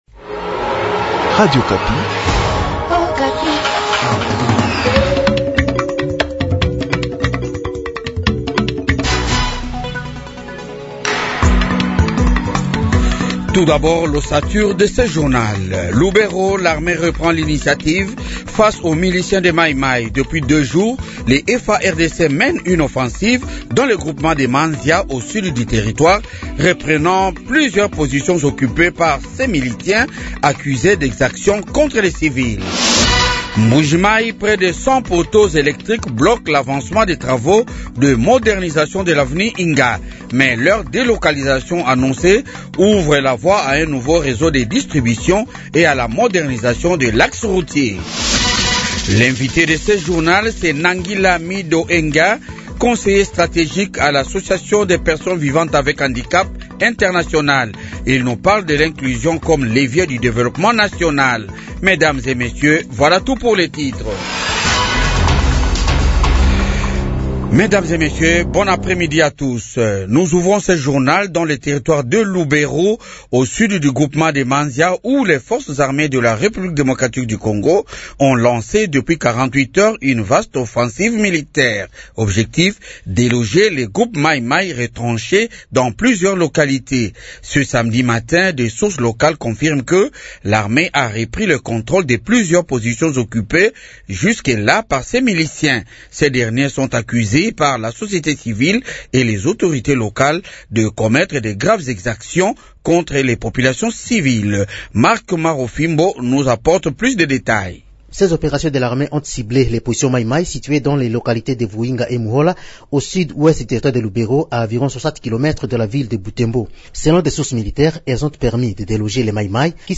Journal de 15h